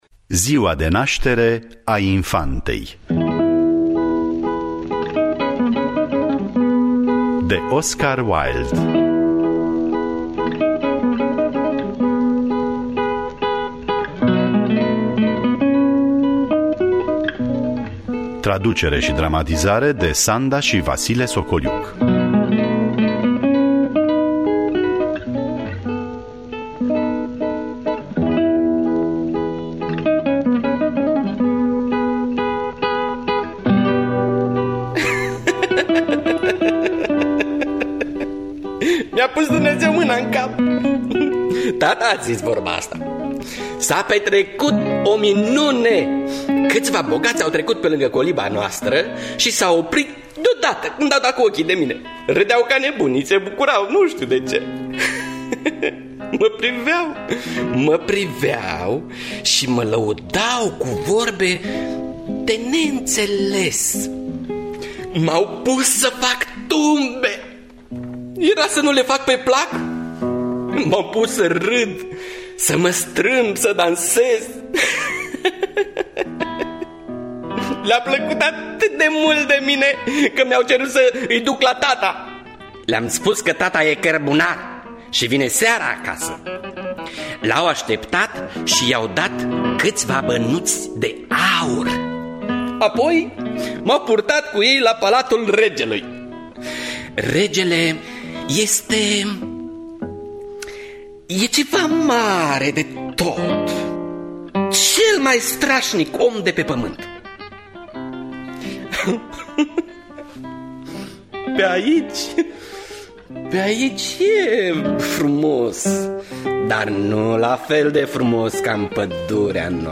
Traducerea si dramatizarea radiofonică